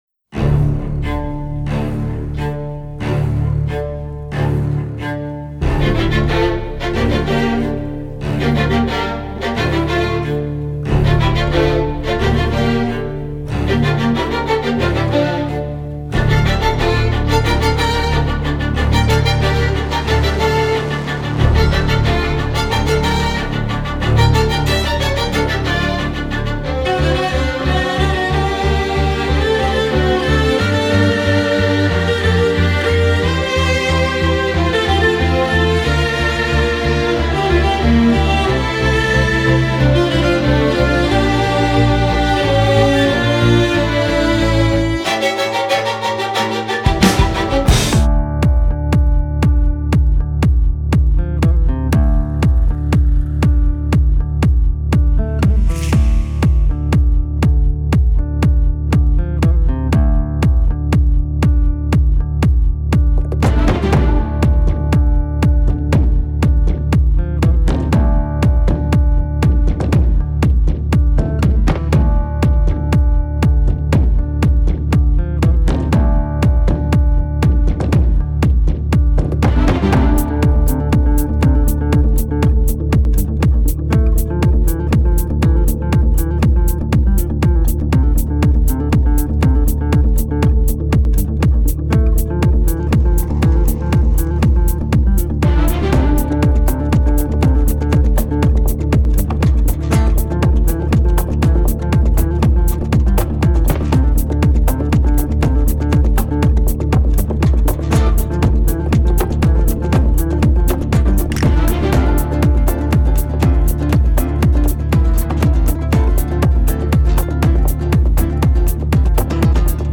Инструментальная